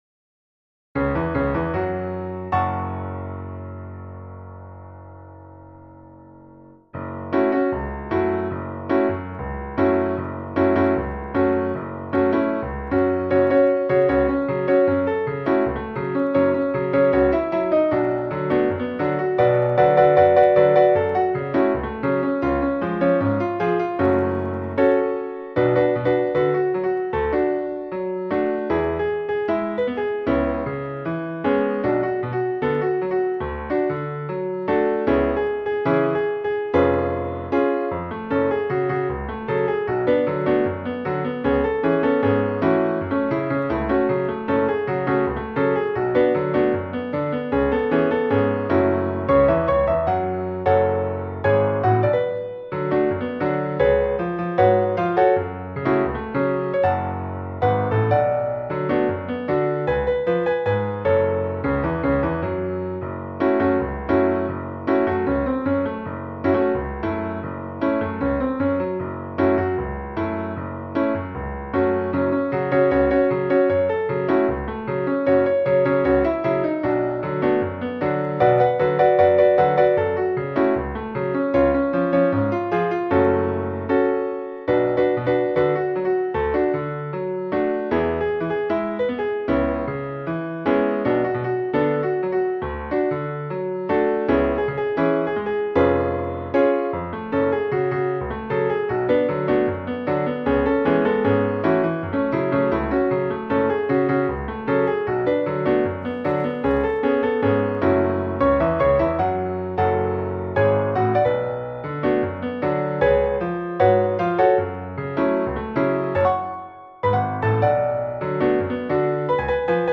• Теги: Ноты для фортепиано
Ноты для фортепиано.